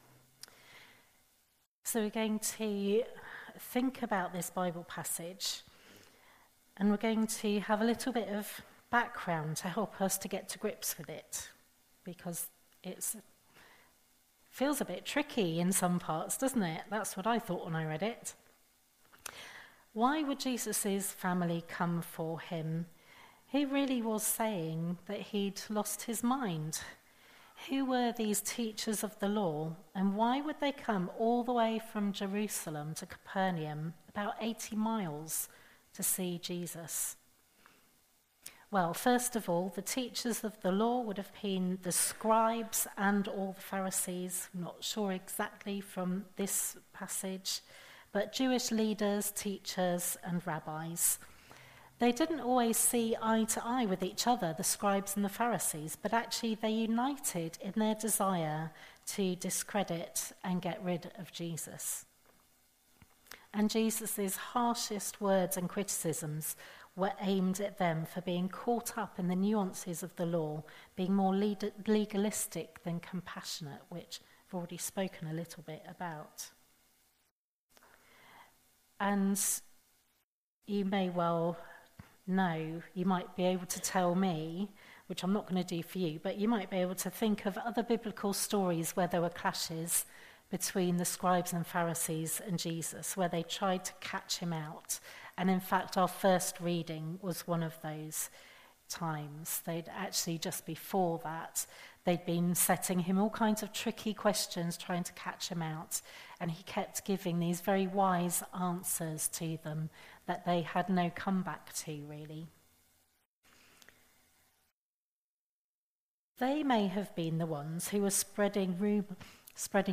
The slides used for the All Age talk can be found in the Notes.
An audio version of the sermon is also available.